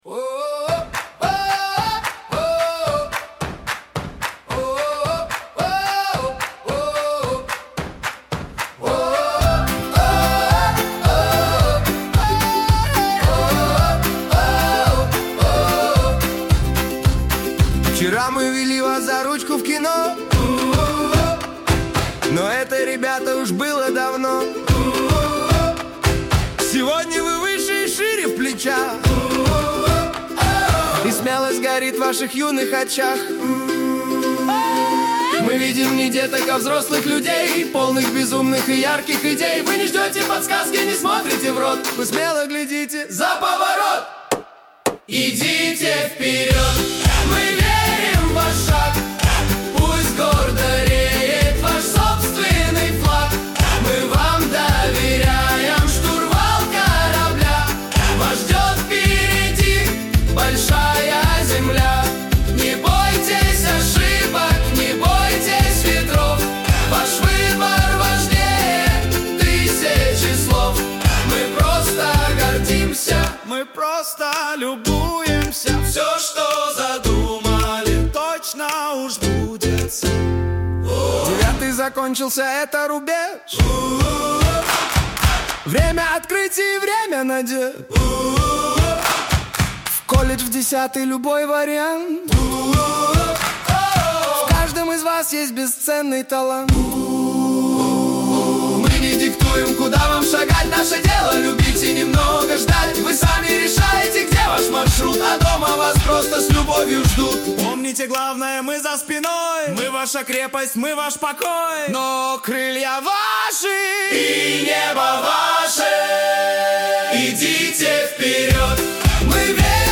🎹 Слушать (Folk Pop / 110 BPM):